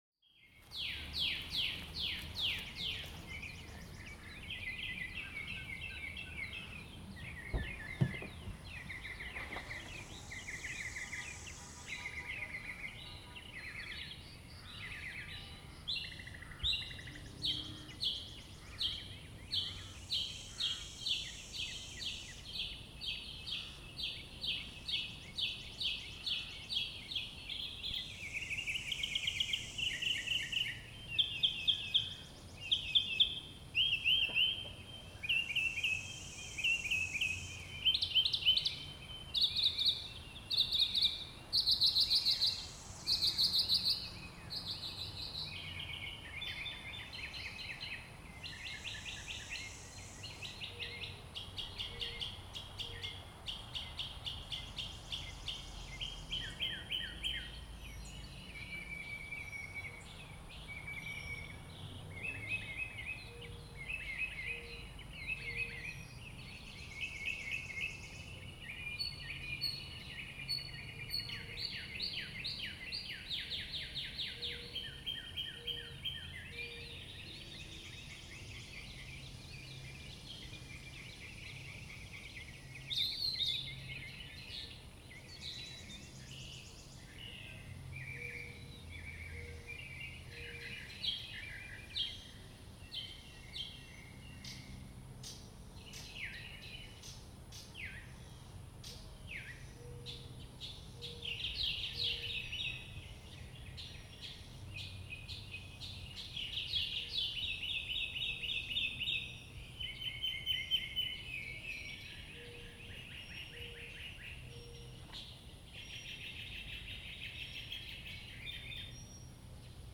Audio File: Birds In A Virginia Cemetery June 2020, 8:45